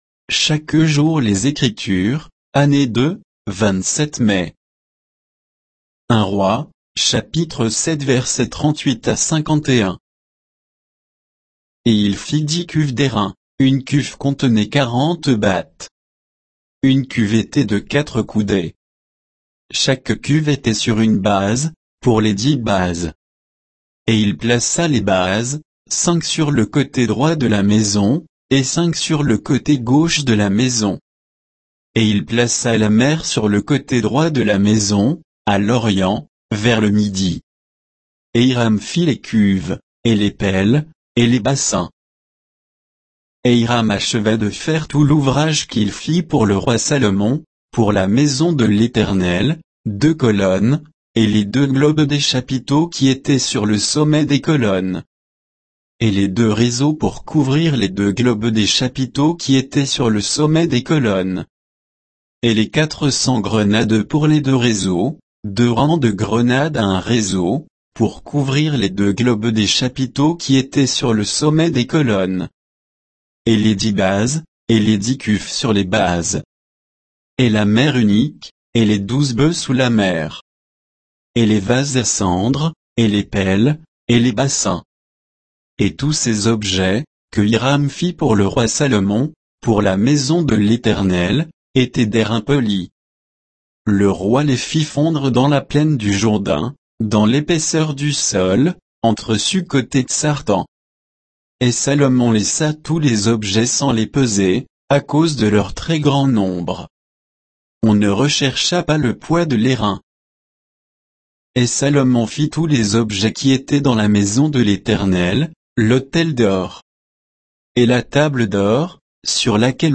Méditation quoditienne de Chaque jour les Écritures sur 1 Rois 7